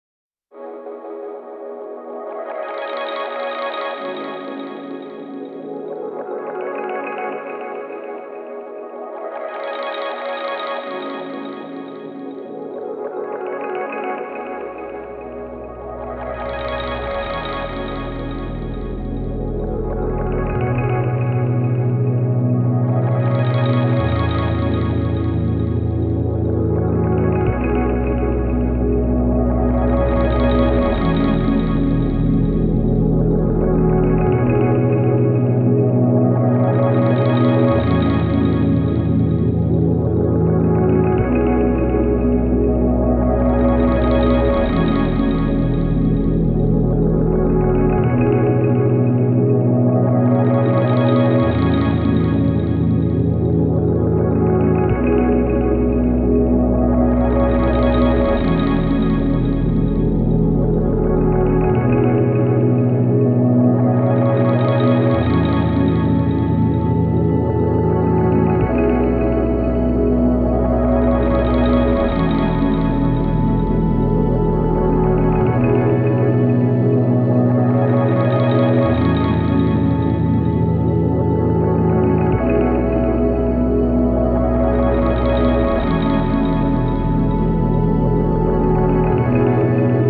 moog